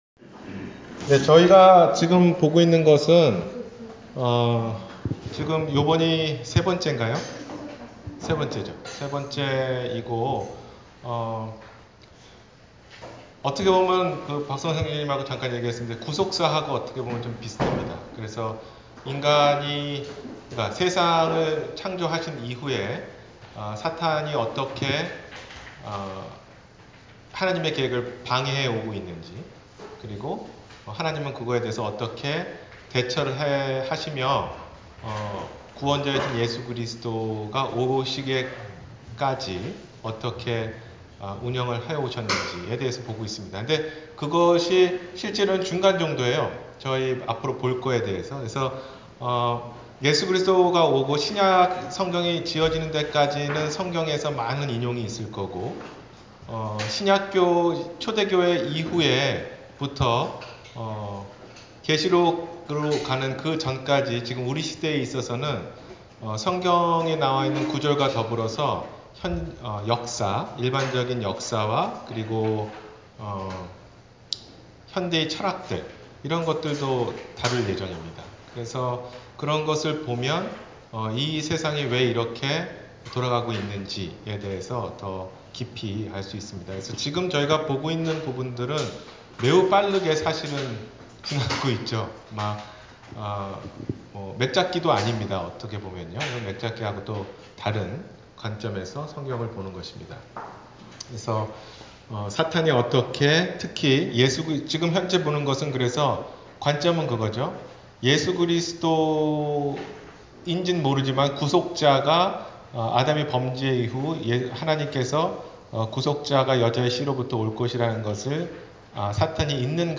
성경으로 보는 역사 3 – 주일성경공부